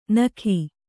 ♪ nakhi